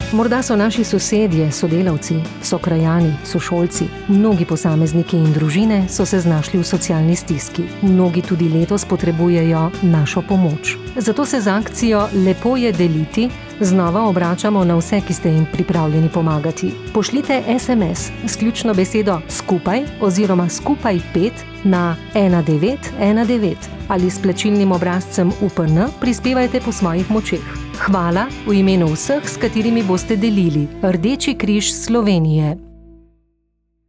Radijski oglas